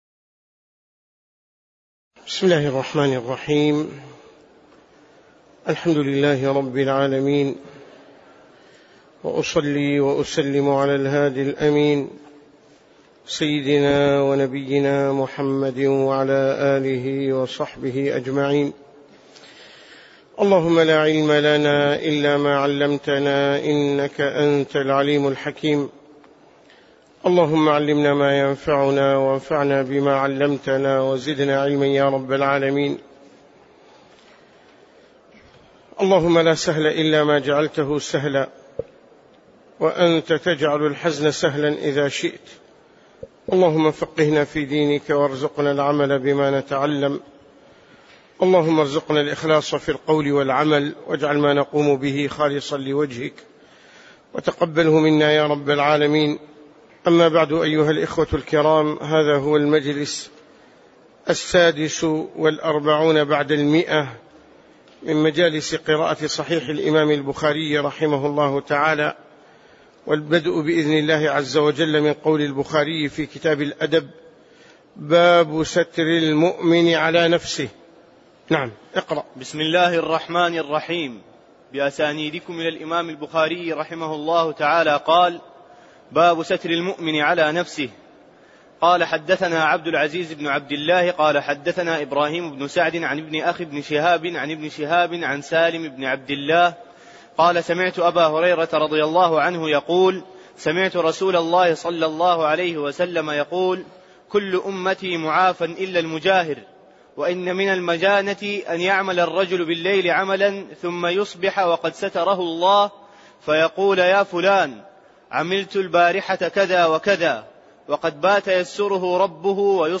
تاريخ النشر ١٤ محرم ١٤٣٩ هـ المكان: المسجد النبوي الشيخ